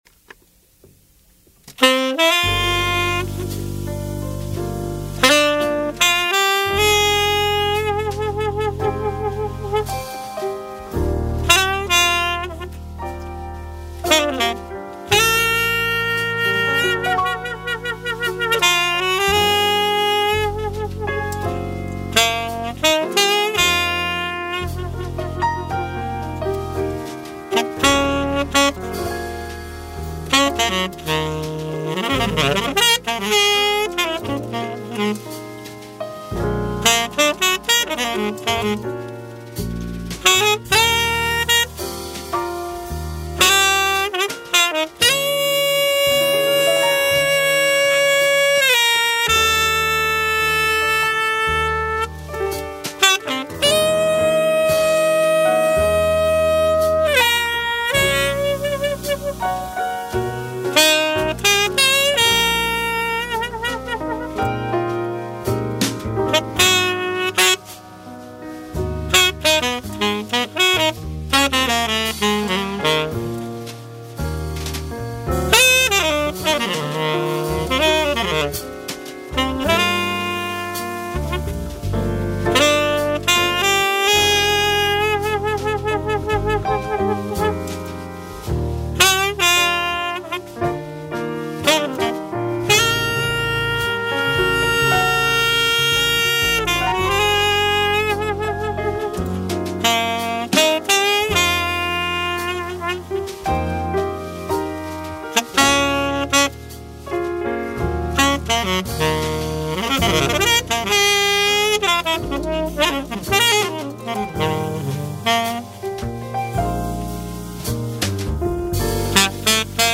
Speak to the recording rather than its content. Click on the button above to download a play-test (then check your "Downloads" folder and select "Open" or "Save As") of the Buffet Crampon Paris E&S Tenor Saxophone with a Yibuy Golden Jazz 7 Mouthpiece